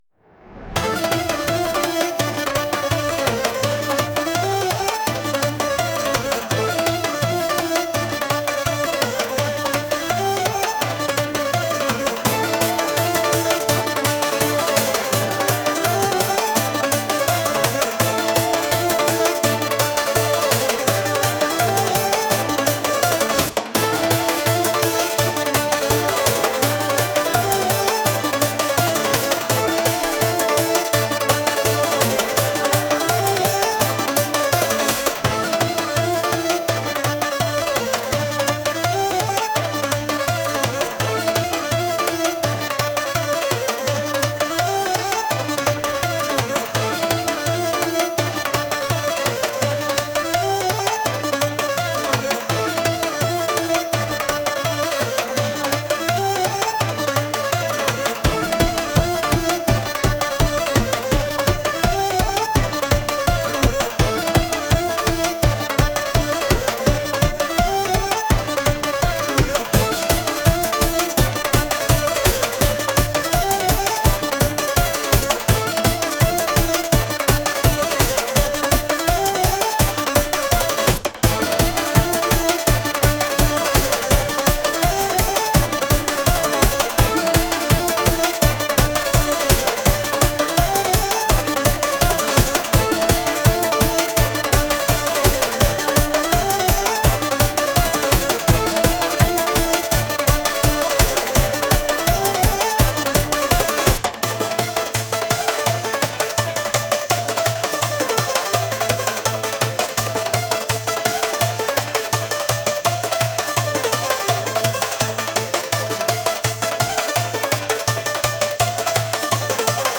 energetic | world | electronic